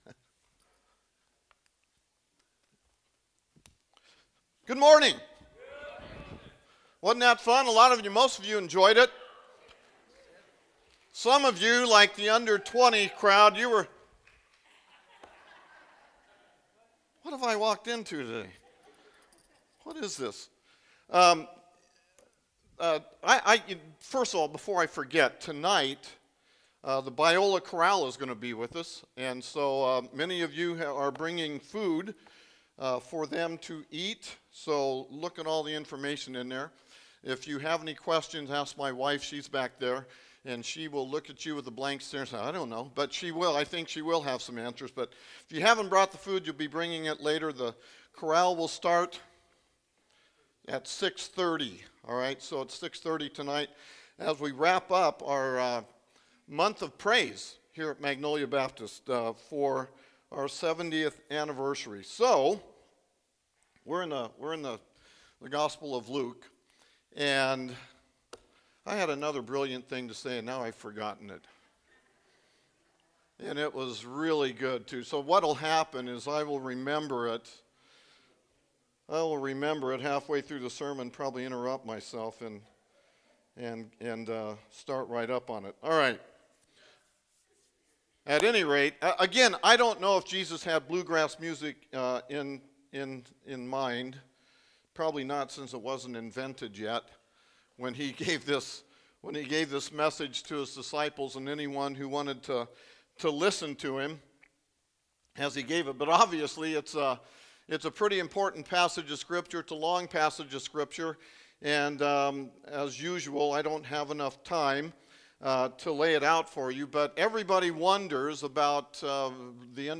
Sermons | Magnolia Baptist Church